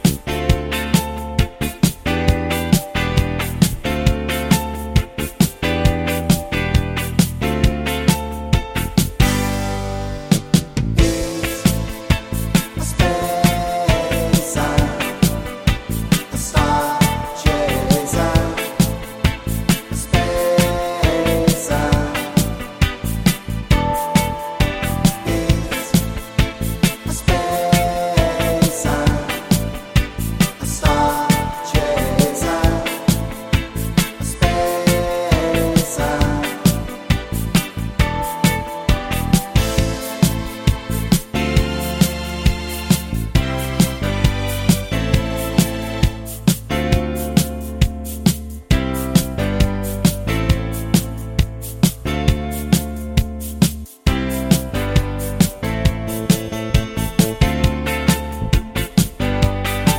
no Backing Vocals Disco 3:54 Buy £1.50